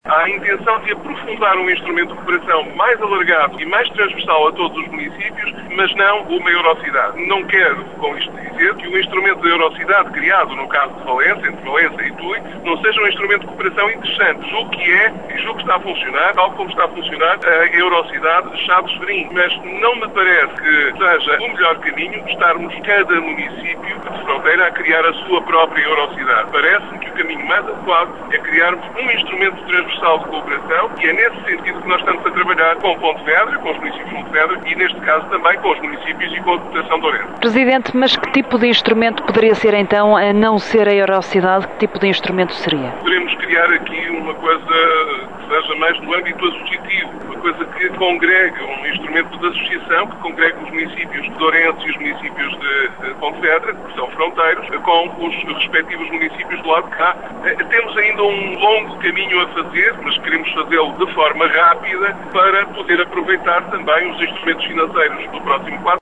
O presidente da Câmara, Manuel Baptista, sublinha a necessidade de avançar rapidamente com o processo.